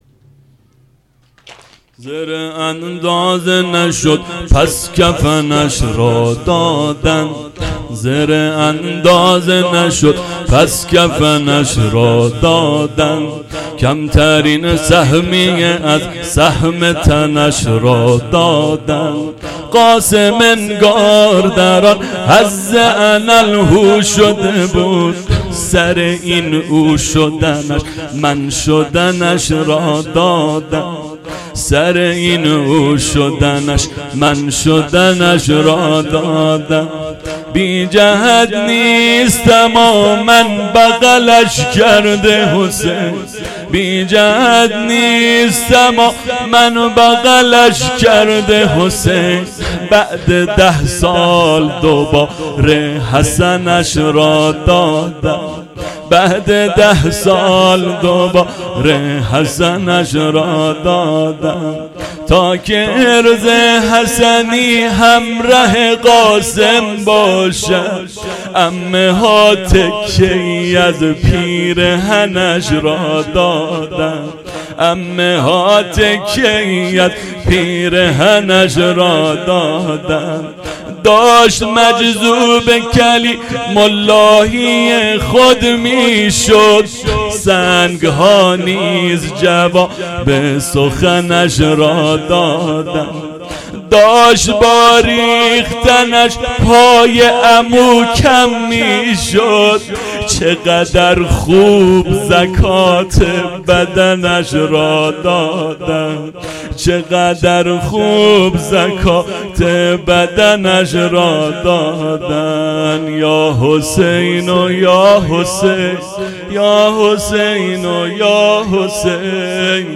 نوحه واحد
شب ششم محرم ۹۷